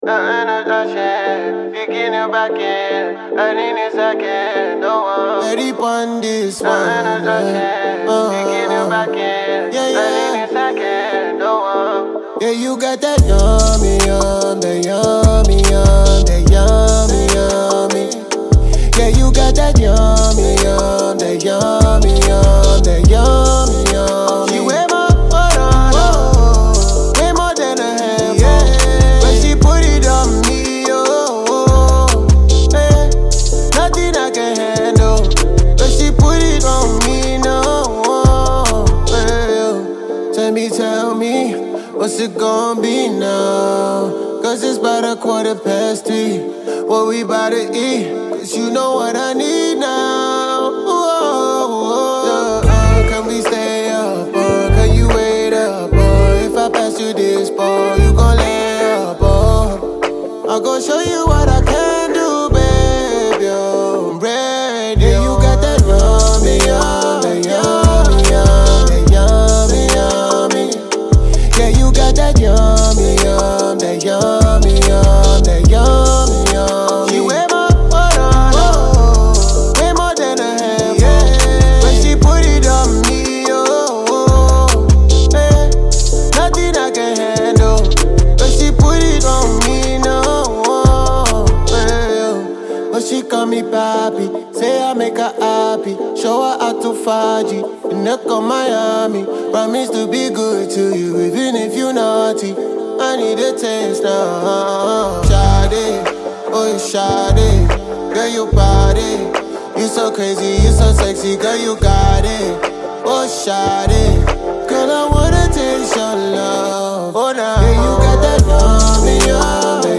with a positive vibes
some AfroBeats flavour